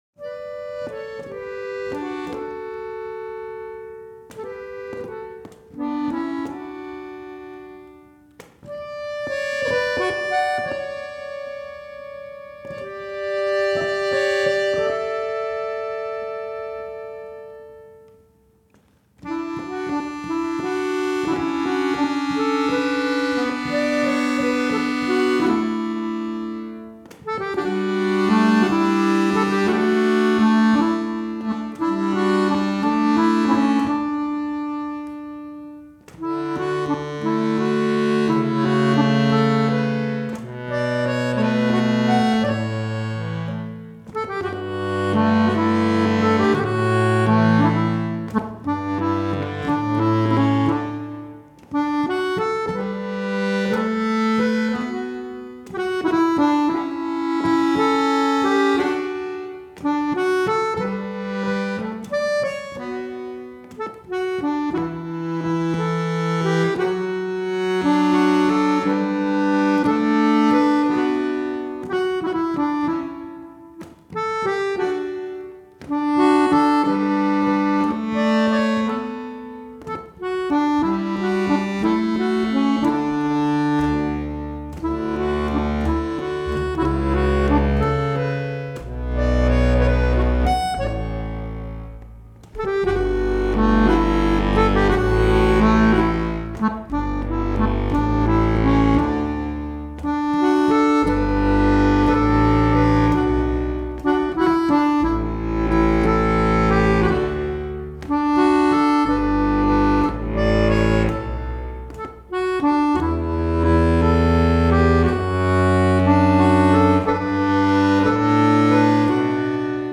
Free-bass accordion, stomp-box, and singing